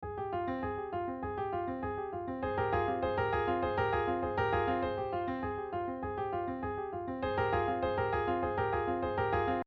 First, a four-note pattern in repetition:
Then, the same theme transposed, imitating the first one.
That’s an ostinato line.
It’s a line repeated with obstinacy.